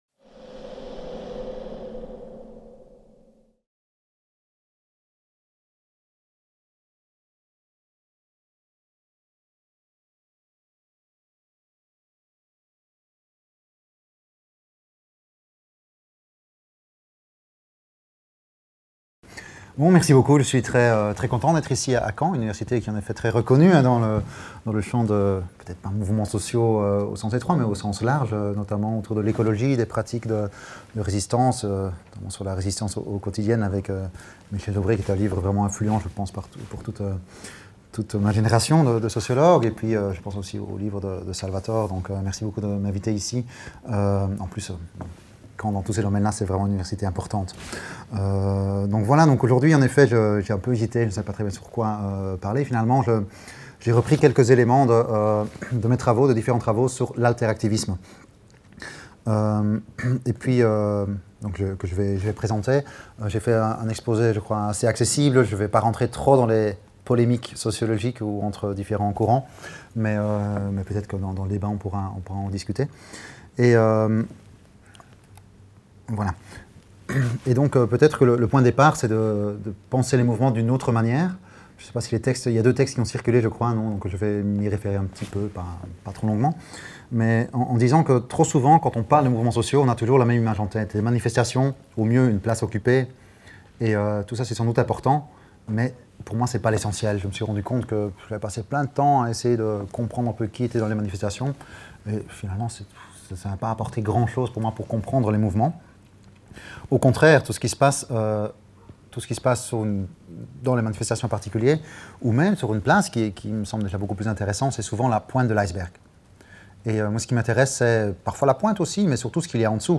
Amphi de la MRSH.